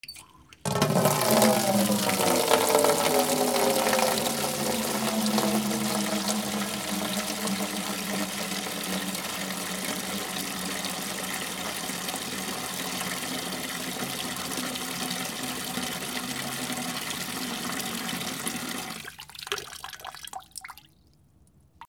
/ C｜環境音(人工) / C-42 ｜火を燃やす / 2_D50
27バケツ（金属）に水を入れる 水道